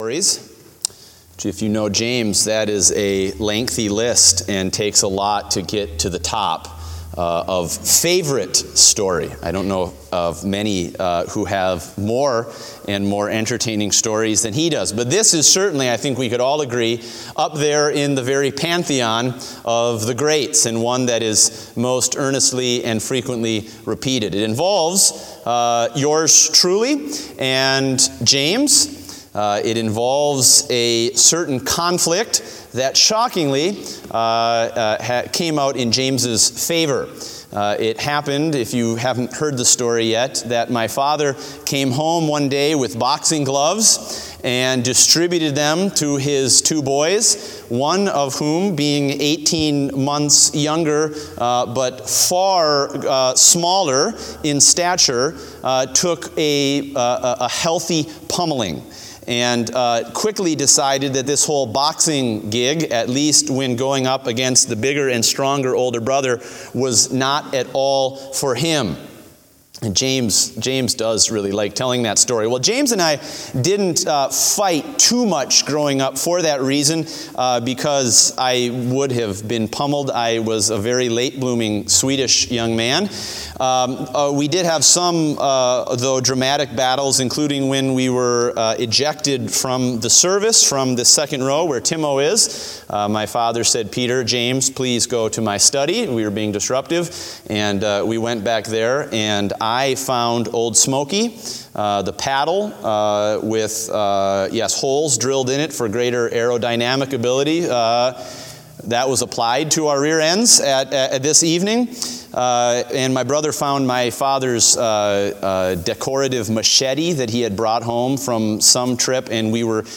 Date: March 26, 2017 (Evening Service)